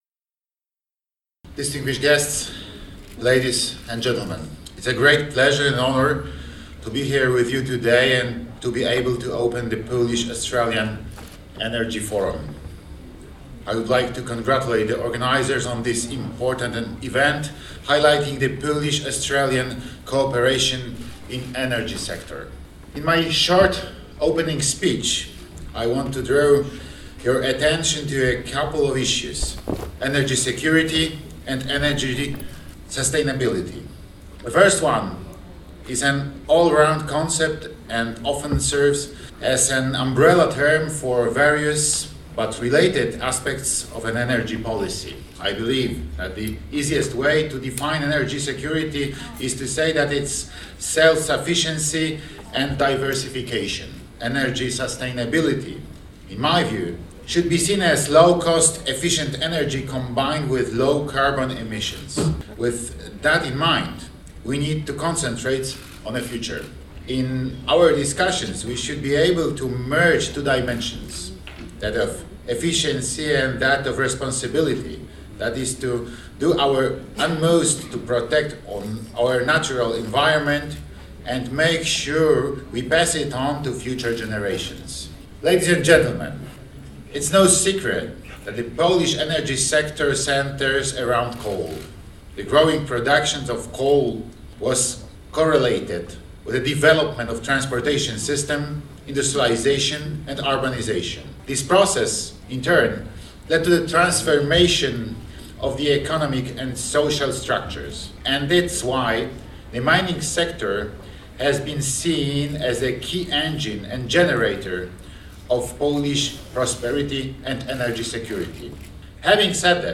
Polish Australian Energy Forum in Sydney 21st August 2018
A oto nagrania Pulsu Polonii - dwa przemówienia w języku angielskim.
Listen to a speech by President Duda.